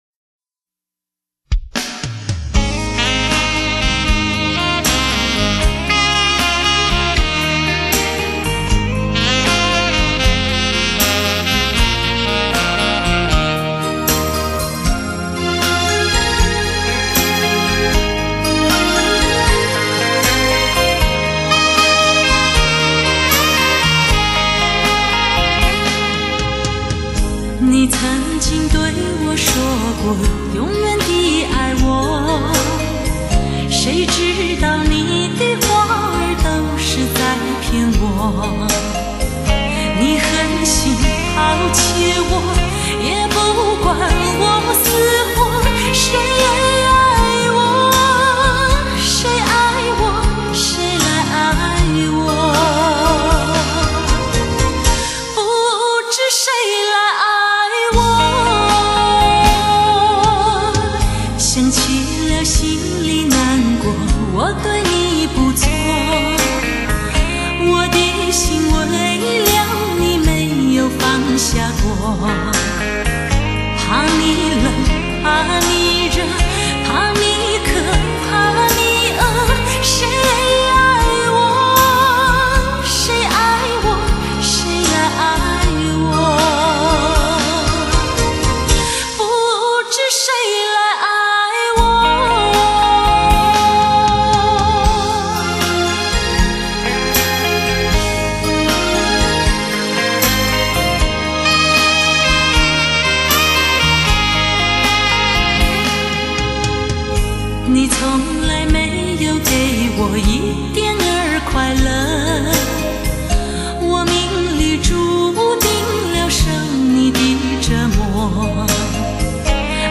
发烧天碟 经典回放 倾情演绎动人老歌
传奇真空管录音，美国DTS-5.1顶级编码器，还原现场震撼体验
全频段六声道制作如同置身音乐厅之中